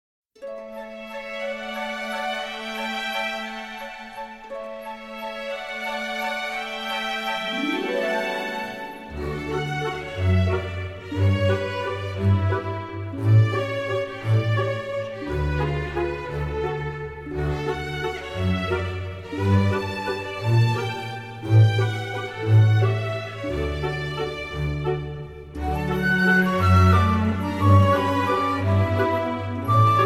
Description Music
Source Soundtrack